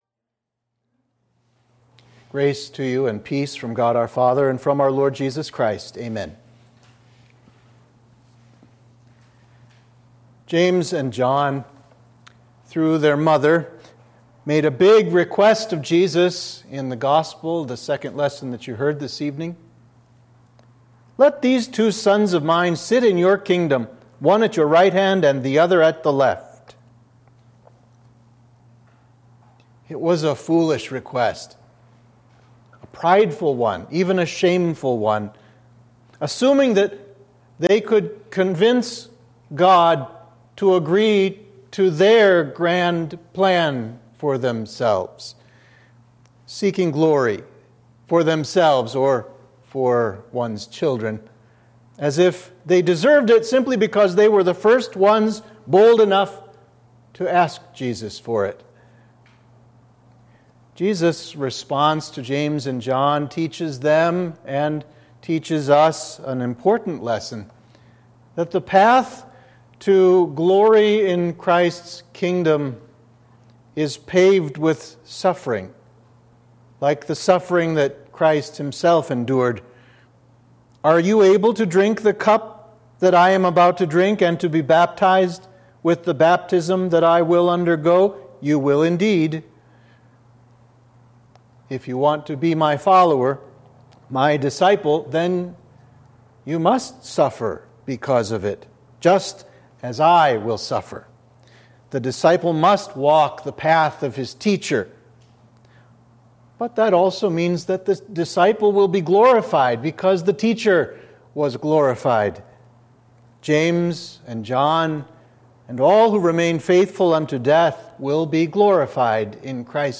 Sermon for the Feast of St. James the Elder (July 25)